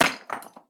Звук камня ударившегося о стену